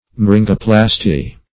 myringoplasty - definition of myringoplasty - synonyms, pronunciation, spelling from Free Dictionary
myringoplasty.mp3